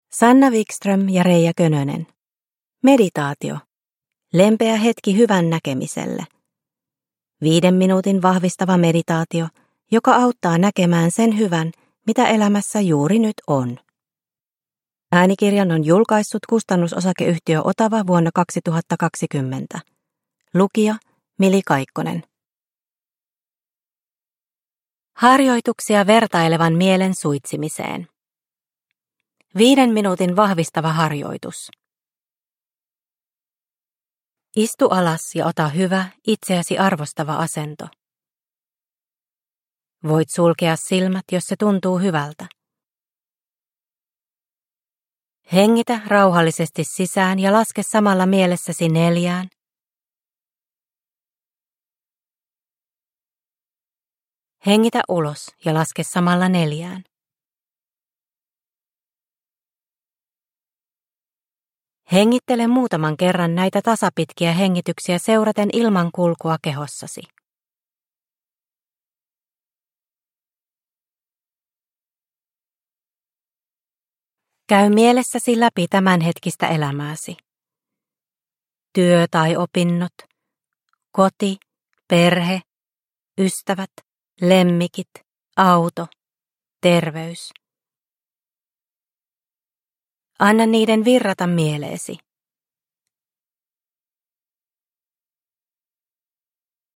Meditaatio - Lempeä hetki hyvän näkemiselle – Ljudbok – Laddas ner